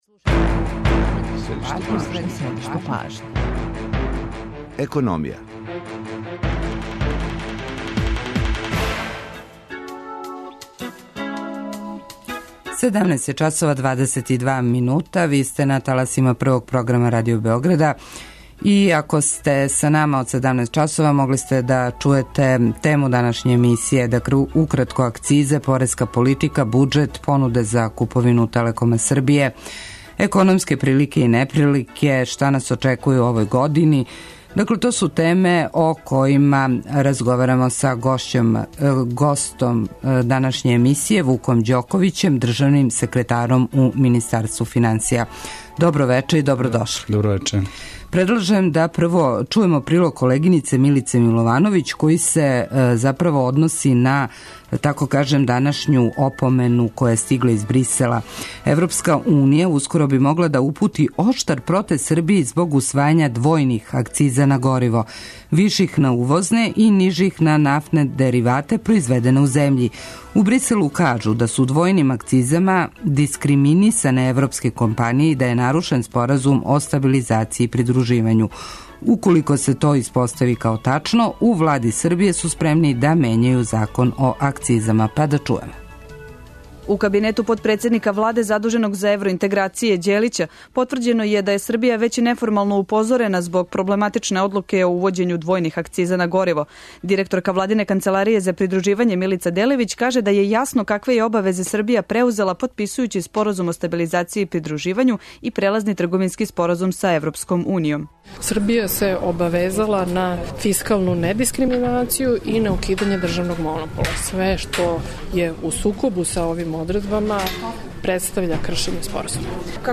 Гост данашње емисије је Вук Ђоковић, државни секретар у Министарству финансија.